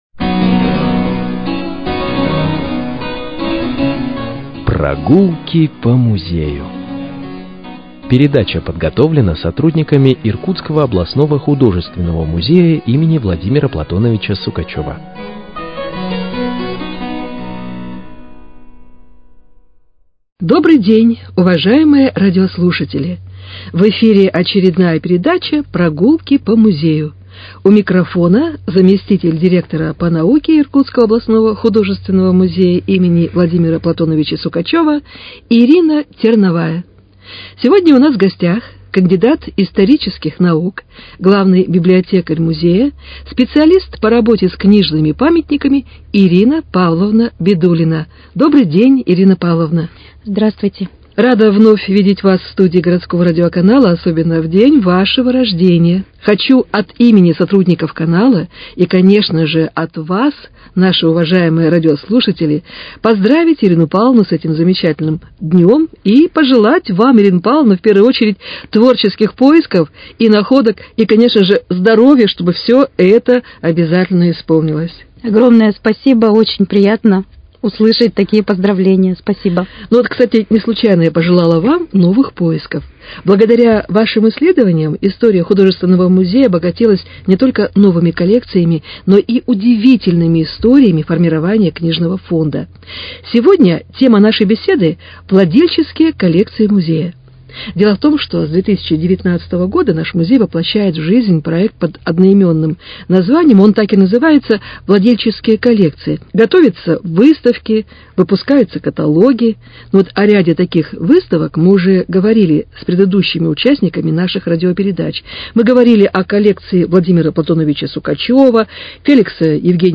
беседует с кандидатом исторических наук